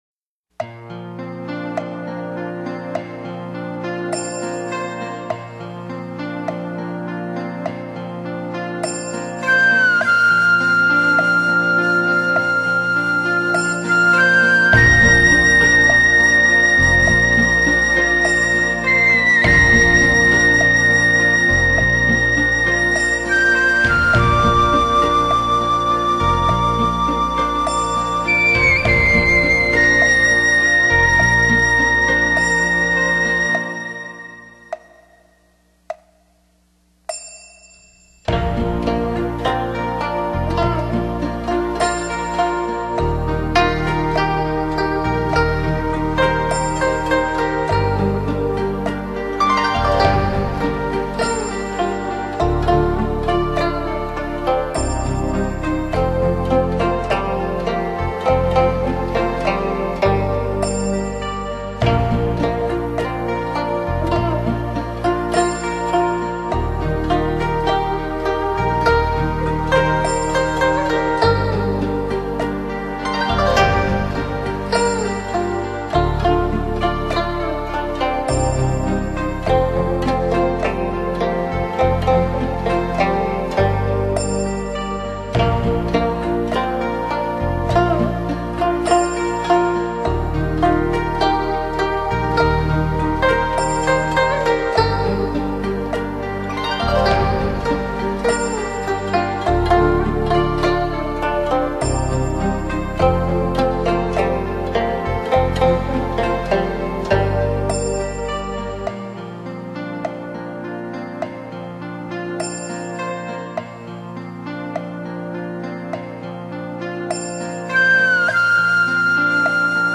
古箏音色內斂變化妙融於佛法中，令人有清淨自在及淨化人心之效，